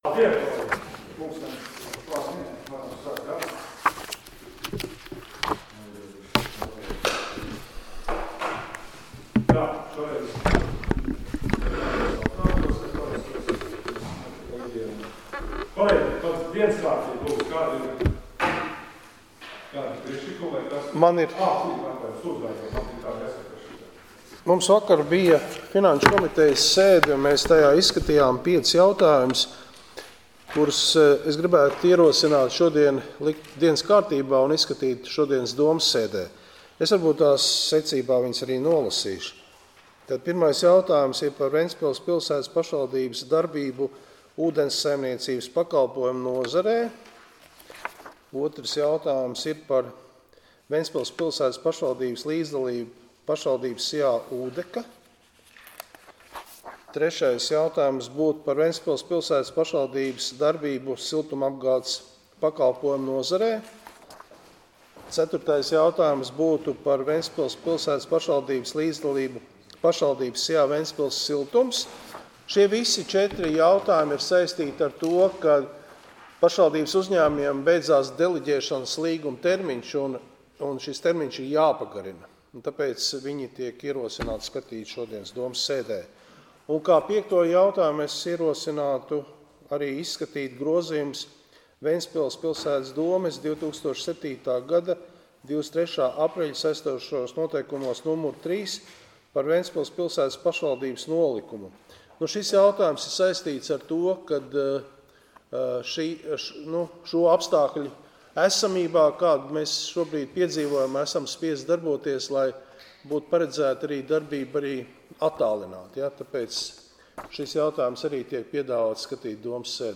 Domes sēdes 13.11.2020. audioieraksts